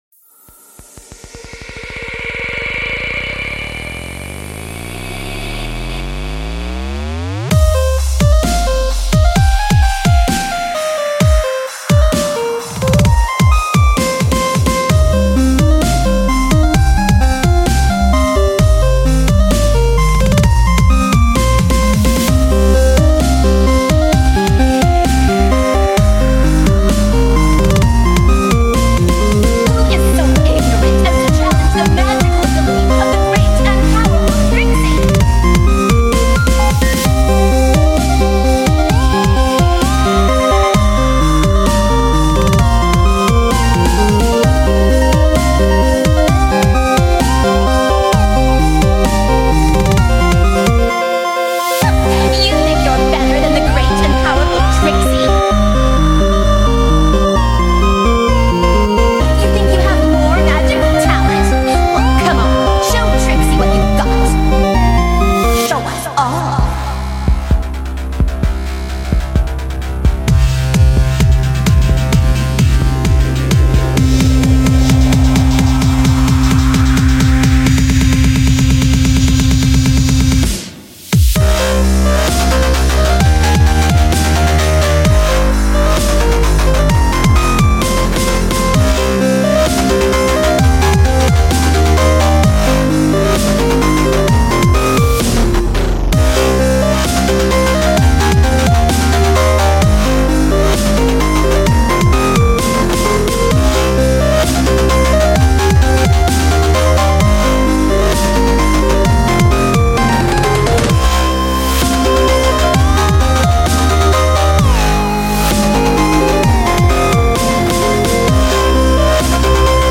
Also from my Trotcon Set!